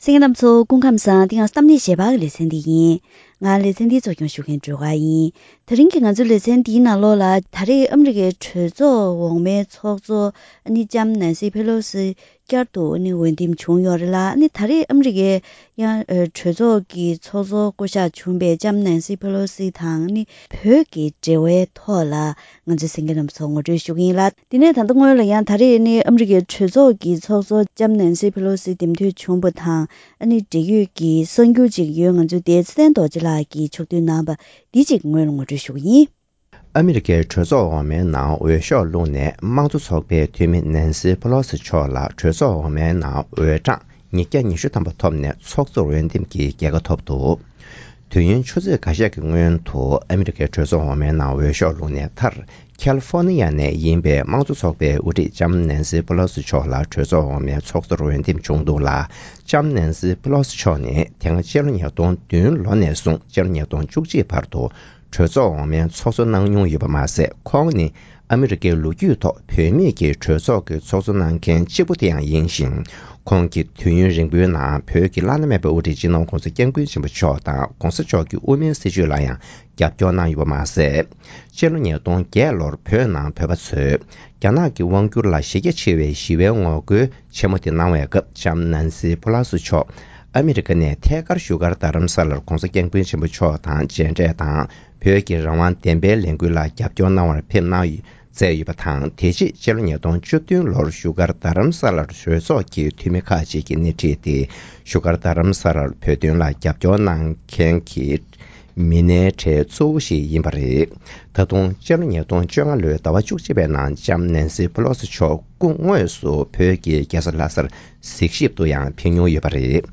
ཨ་རིའི་གྲོས་ཚོགས་ཀྱི་ཚོགས་གཙོར་ལྕམ་ནེན་སི་ཕེ་ལོ་སི་མཆོག་འདེམས་ཐོན་བྱུང་བ་དེས་བོད་དང་བོད་མིའི་འབྲེལ་བ་དང༌མ་འོངས་པར་ཨ་རི་དང་རྒྱ་ནག་གི་འབྲེལ་བ་སོགས་ཀྱི་ཐད་བཀའ་མོལ་ཞུས་པ།